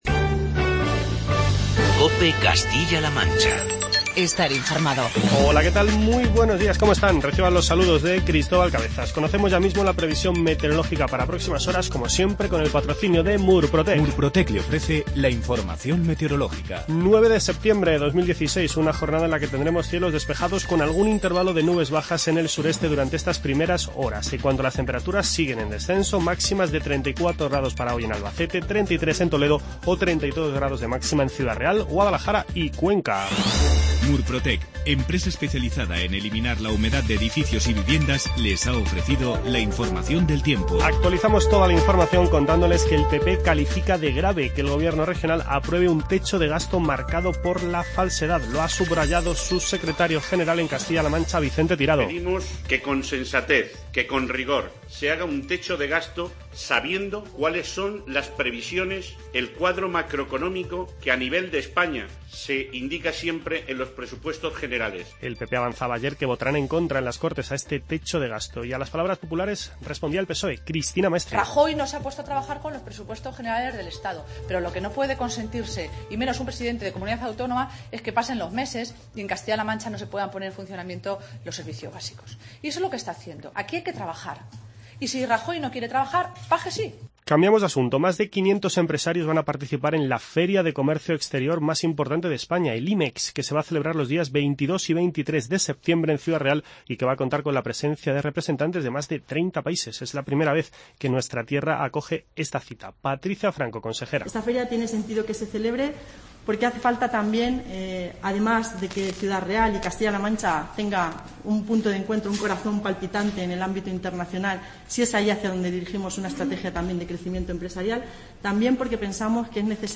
El Partido Popular de Castilla-La Mancha califica de "grave" que el Gobierno regional apruebe un techo de gasto marcado por la falsedad. Son declaraciones del secretario general del PP de Castilla-La Mancha, Vicente Tirado.